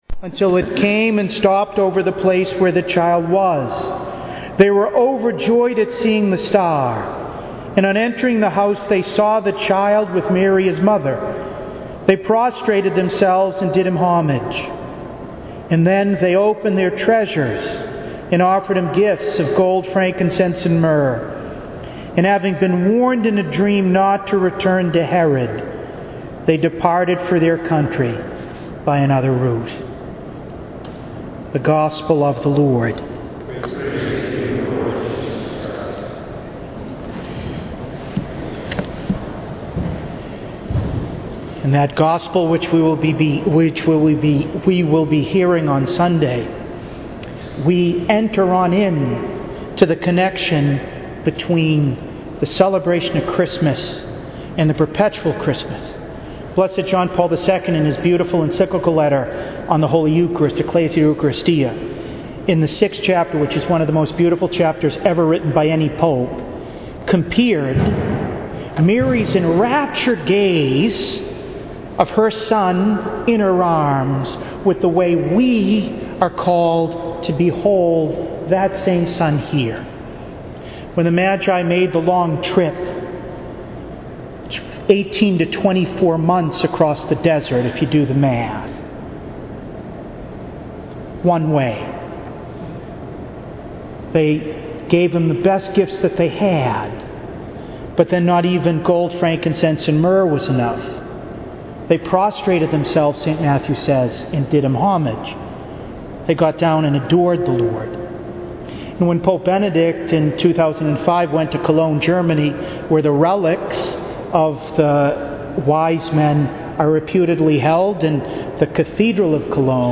Retreat for the Seminarians of Cathedral Seminary House of Formation, Douglaston, New York at Immaculate Conception Seminary, Huntington, NY January 2-6, 2014
To listen to an audio recording of this conference, please click below: